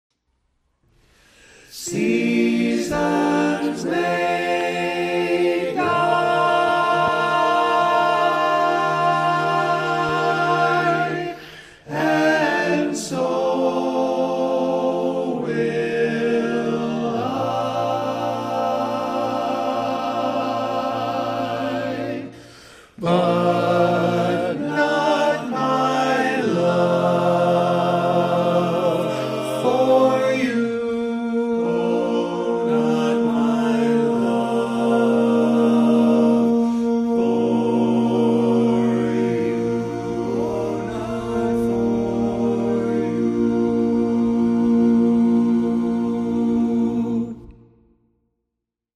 Key written in: B♭ Major
How many parts: 4
Type: Barbershop
Learning tracks sung by